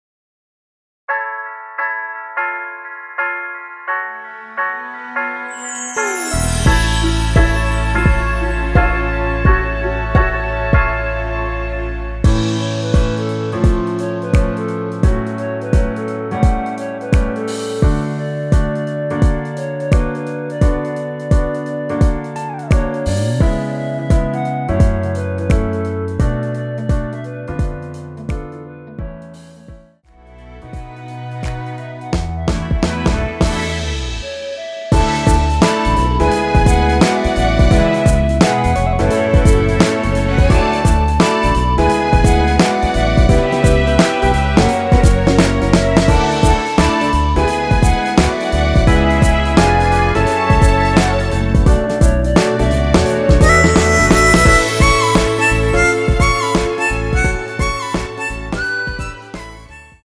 엔딩이 페이드 아웃이라서 노래하기 편하게 엔딩을 만들어 놓았으니 코러스 MR 미리듣기 확인하여주세요!
원키에서(+1)올린 멜로디 포함된 MR입니다.
앞부분30초, 뒷부분30초씩 편집해서 올려 드리고 있습니다.
곡명 옆 (-1)은 반음 내림, (+1)은 반음 올림 입니다.
(멜로디 MR)은 가이드 멜로디가 포함된 MR 입니다.